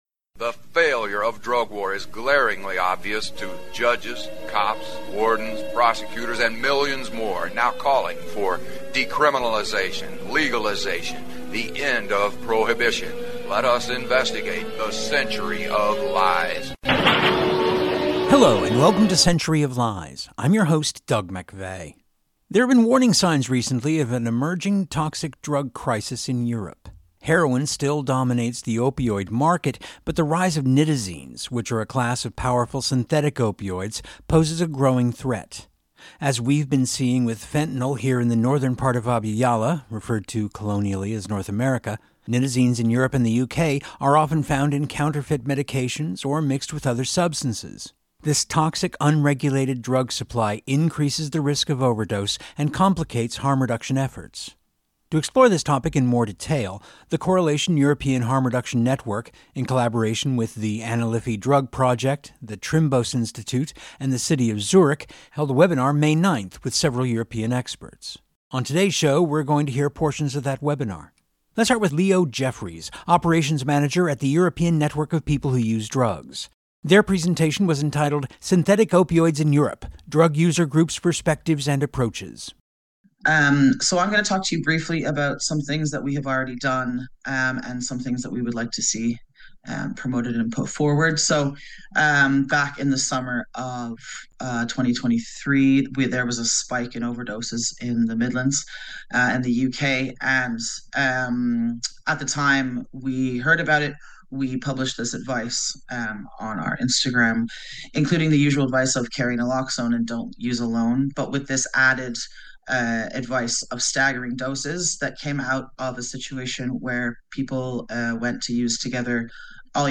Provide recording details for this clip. On this edition of Century we hear portions of that webinar, including presentations